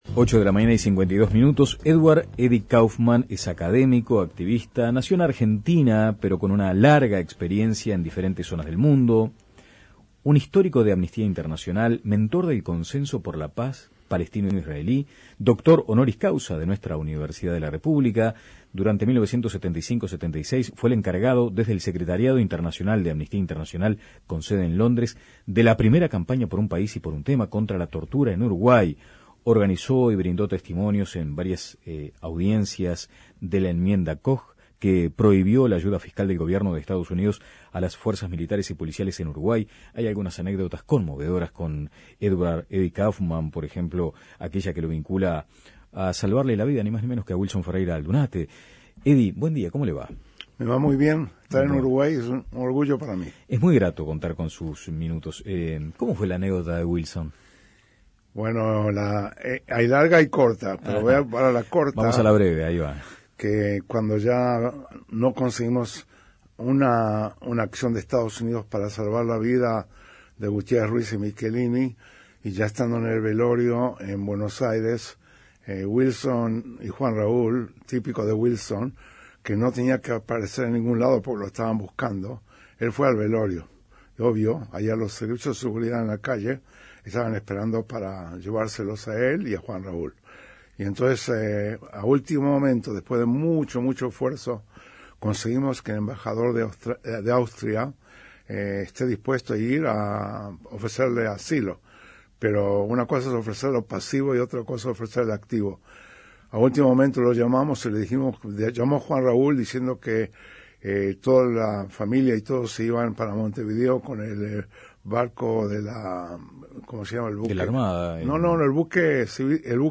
Escuche la entrevista completa aquí: Descargar Audio no soportado